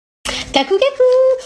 Kakou Kakou Meme Effect sound effects free download